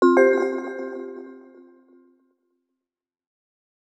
Pickup Soft.wav